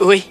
Voix gratuites hommes
Ouch